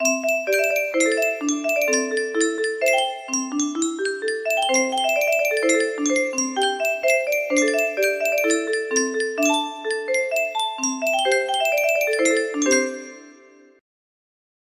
Yunsheng Music Box - Unknown Tune Y525 music box melody
Full range 60